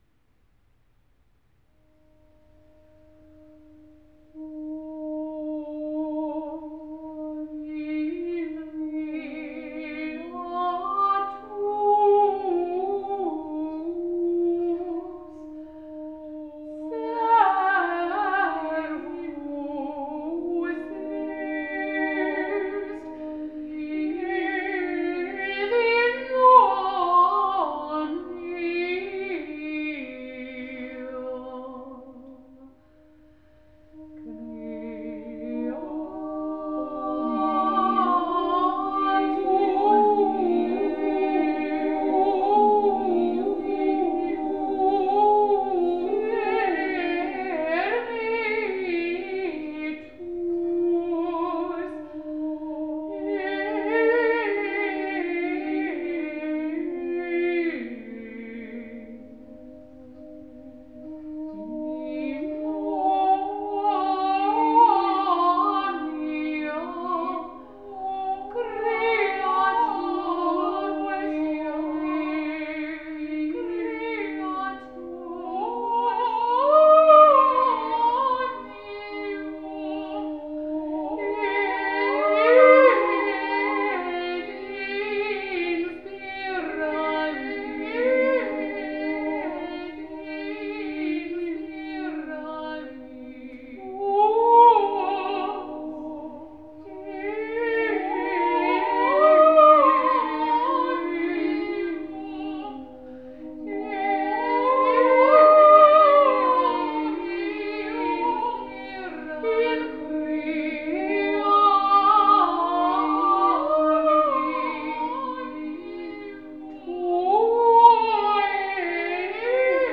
Two new Sound Healings
an improvisation from Spirit
with many Voices